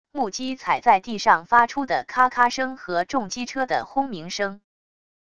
木屐踩在地上发出的咔咔声和重机车的轰鸣声wav音频